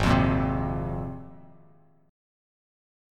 G#6add9 chord